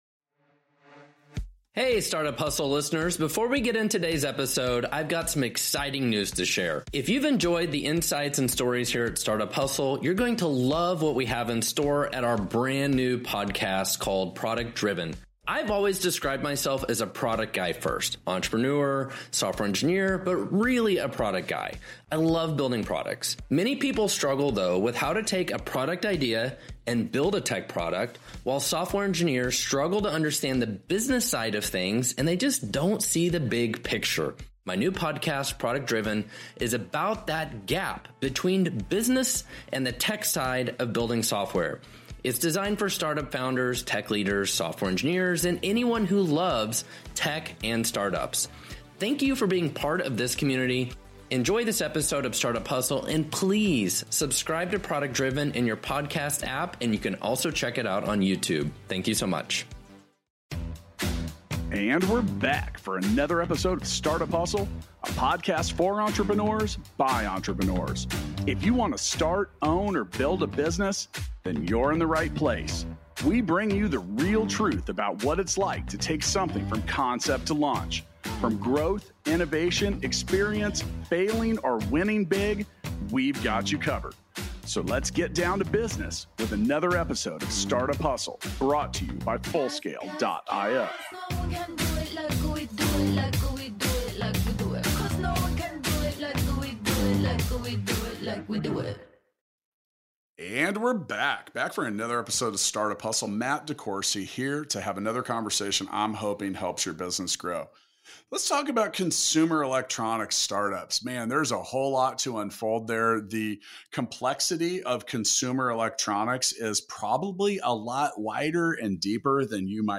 for a conversation about Consumer Electronics Startups. Hear the real story behind managing consumer electronics products, from funding to product development to product fulfillment.